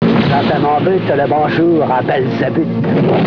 Extraits de Dialogue :